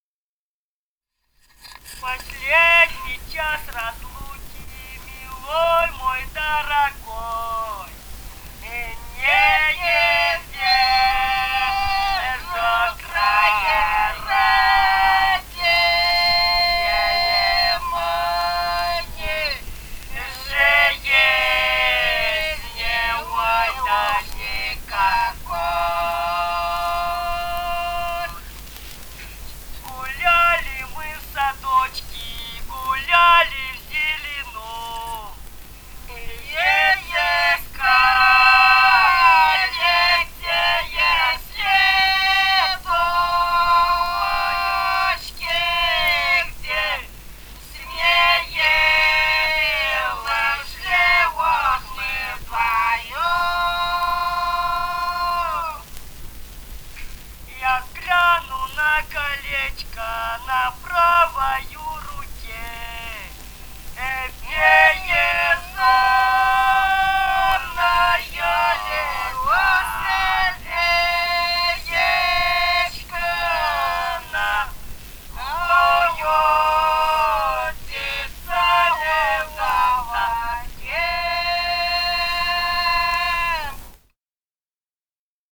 Этномузыкологические исследования и полевые материалы
«Последний час разлуки» (лирическая).
Бурятия, с. Желтура Джидинского района, 1966 г. И0904-09